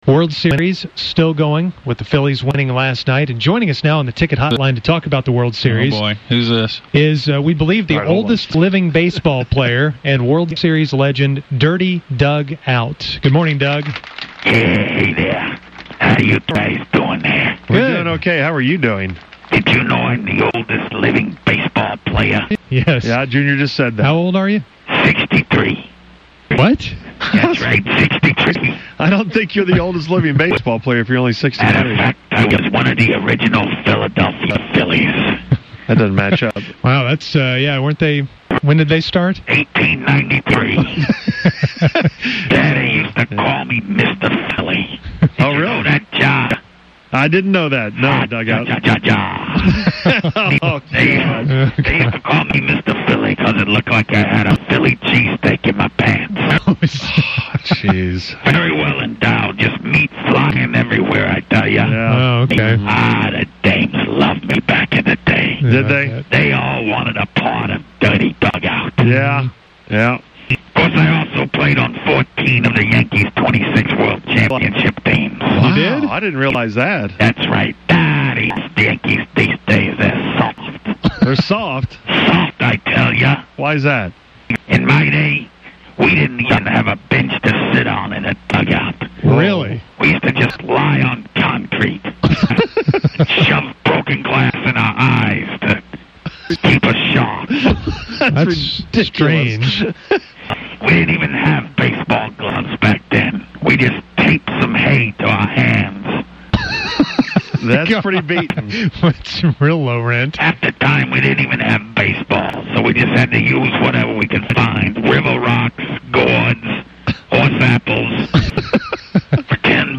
He sounds like a dog from the cartoon that takes a bite out of crime, which he references at the end.